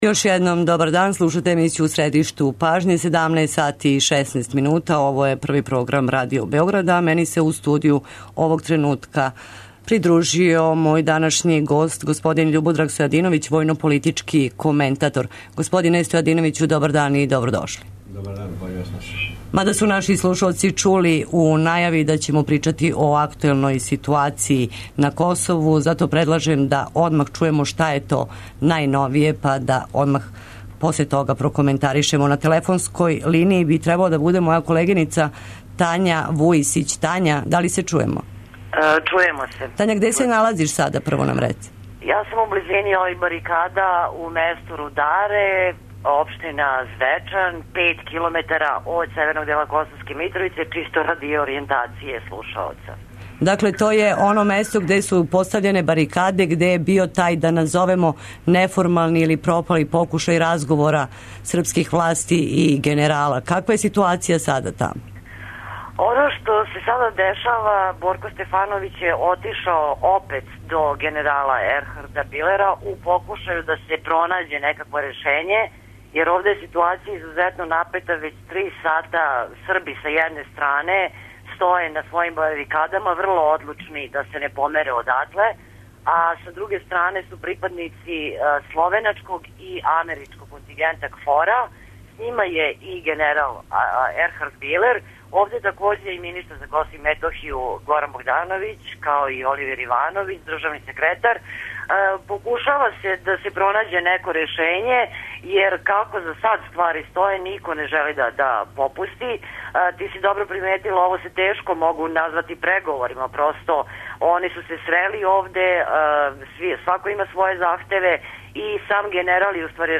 Да ли ће и када преговори Београда и Приштине бити настављени? Ово су нека од питања на која ће у емисији "У средишту пажње" покушати да одговоре извештачи с лица места, аналитичари и представници Владе Србије.